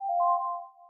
interface_sounds
MessageIn.wav